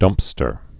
(dŭmpstər)